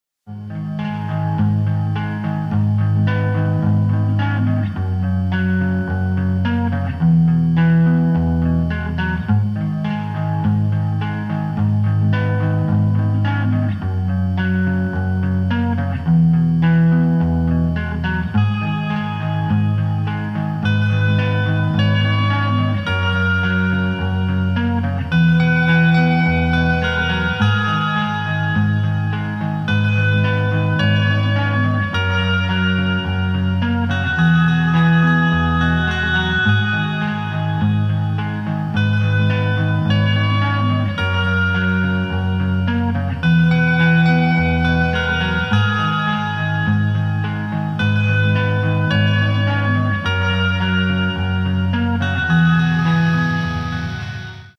• Качество: 256, Stereo
мелодичные
спокойные
без слов
электрогитара
post-rock
бас-гитара
melodic metal